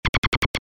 Pad + Kick + Bass: